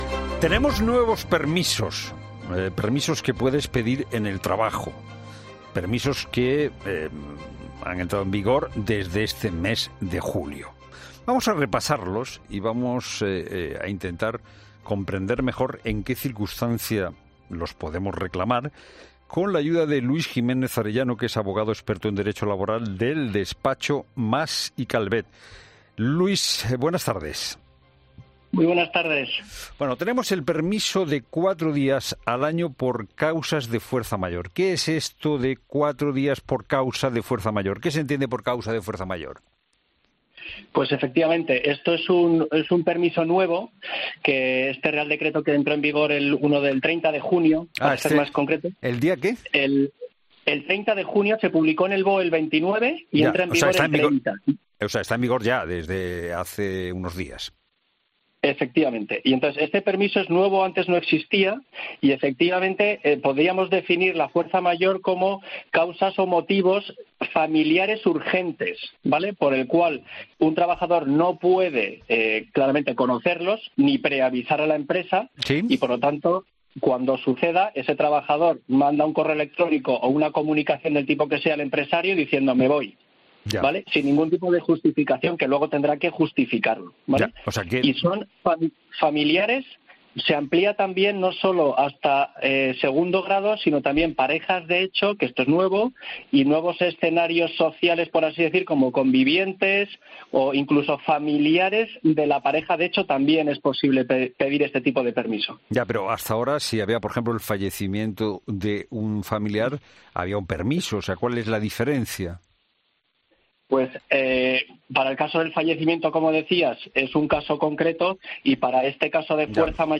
Esta semana en La Tarde hemos podido hablar con el abogado experto en derecho laboral para aclarar cuáles son los nuevos escenarios que tenemos ahora en el trabajo